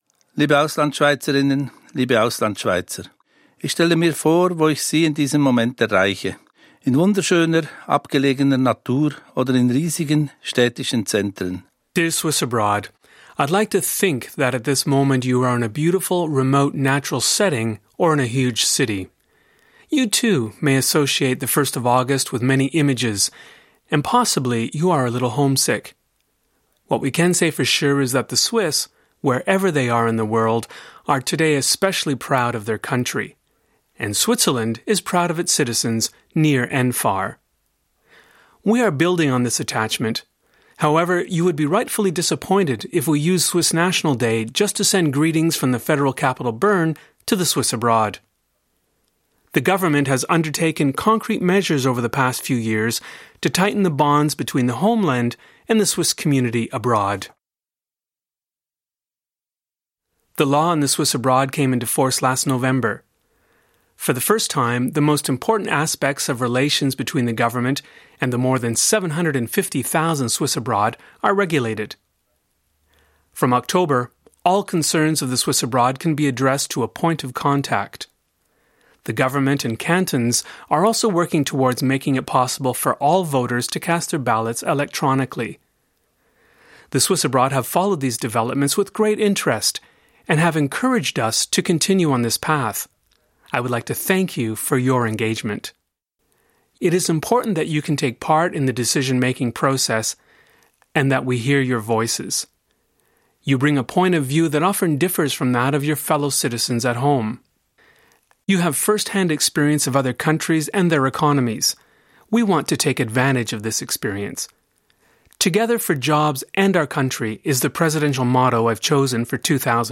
President Johann N. Schneider-Amman’s speech to the Swiss abroad on the Swiss national day.